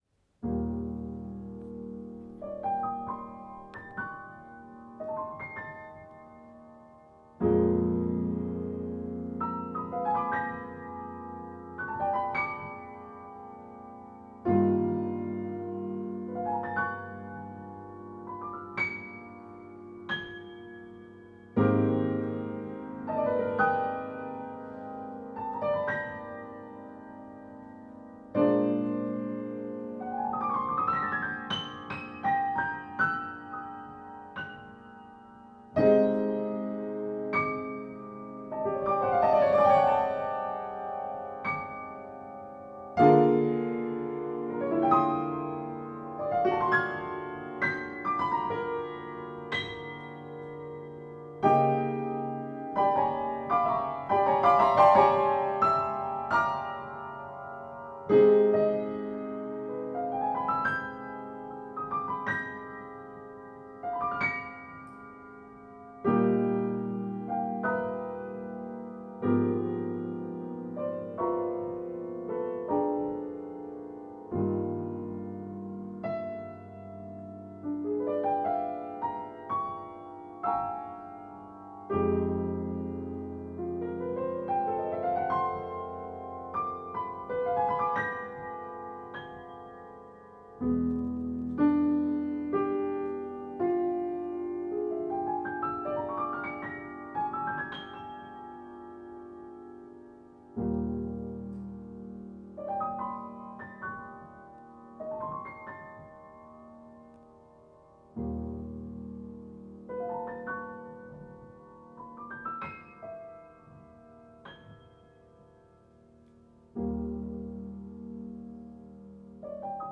Instrumentación: piano solo